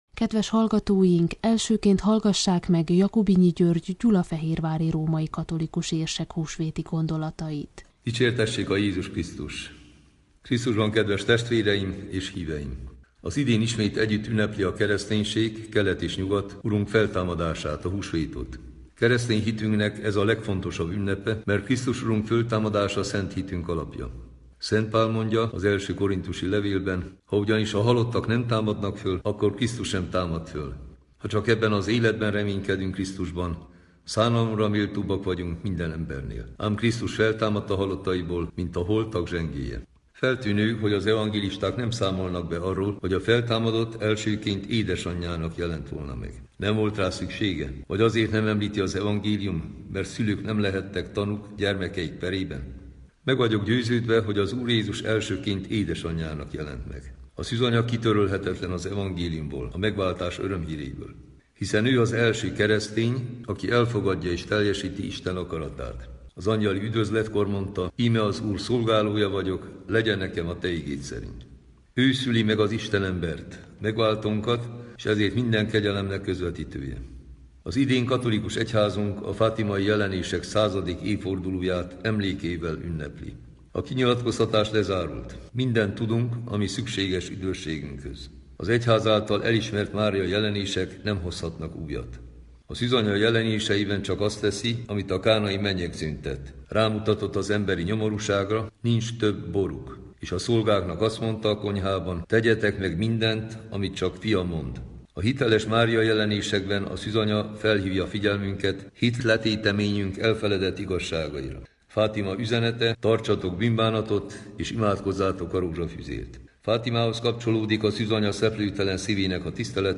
A négy történelmi egyházfő ? Jakubinyi György római-katolikus érsek, Kató Béla református-, Bálint-Benczédi Ferenc unitárius- és Adorjáni Dezső evangélikus püspök mond húsvéti üzenetet.